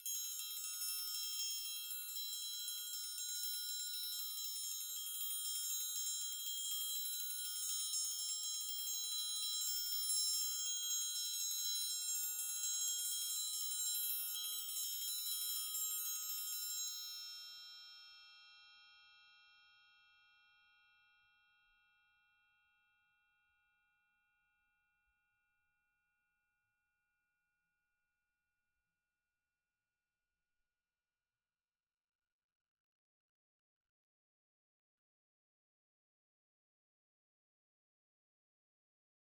Percussion
Triangle3-Roll_v2_rr1_Sum.wav